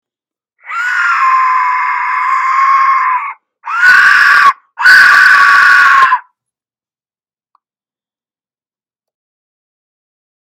New Aztec Death Whistle 2022 scary frightening sounds gut wrenching hurdeling scream!
Large Aztec Death whistle
The Aztec Death whistle is a hand crafted musical instrument producing the loudest, scariest, terrifying sound around.